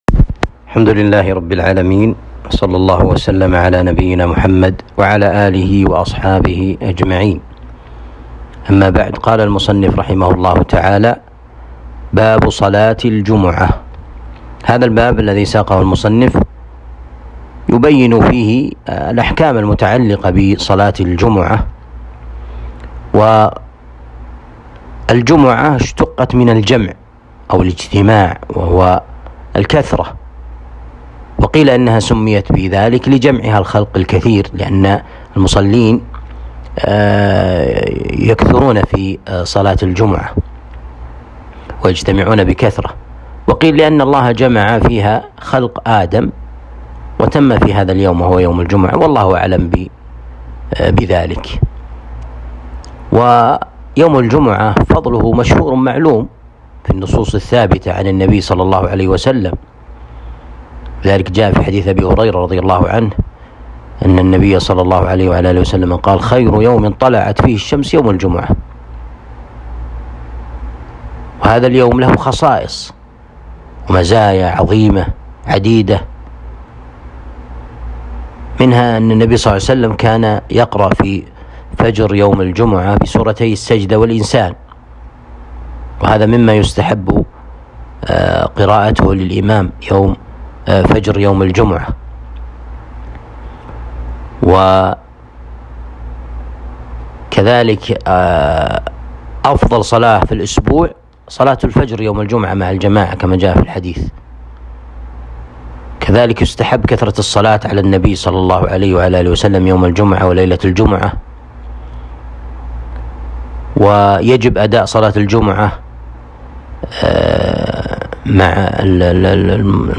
الدروس شرح كتاب آداب المشي إلى الصلاة